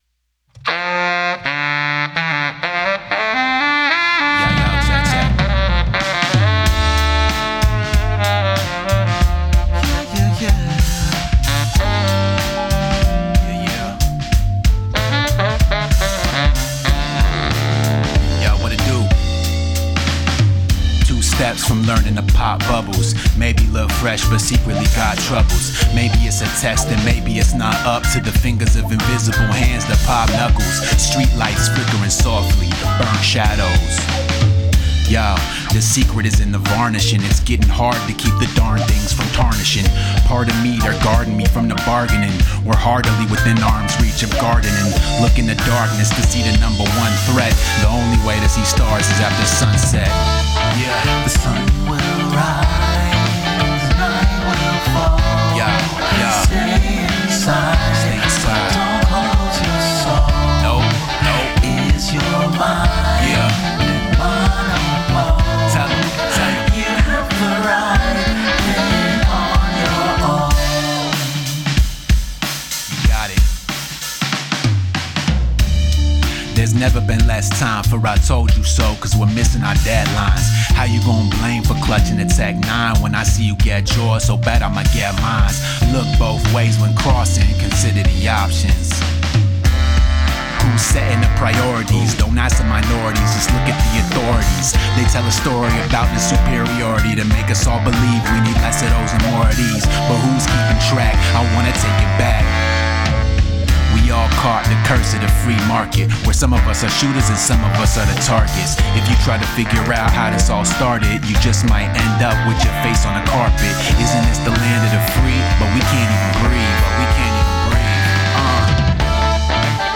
Sentimental, Laid back - Jazz / Hip-hop / Rap / Horns